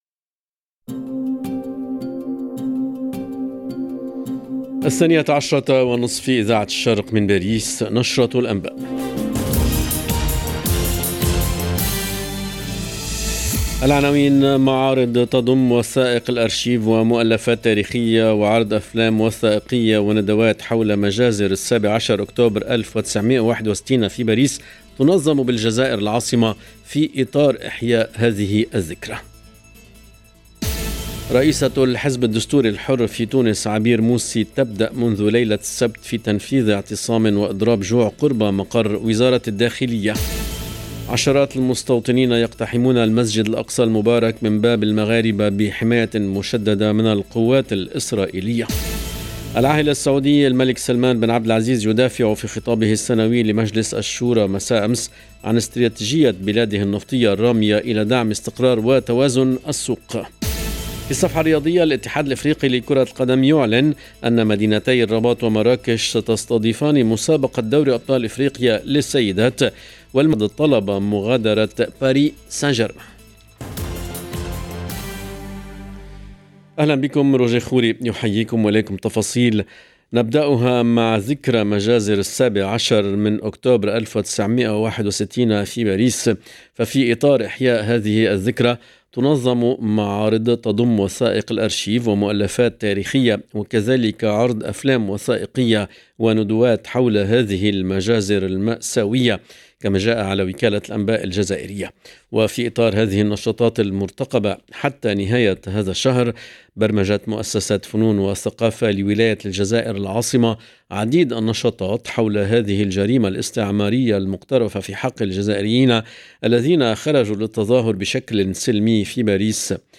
LE JOURNAL EN LANGUE ARABE DE MIDI 30 DU 17/10/22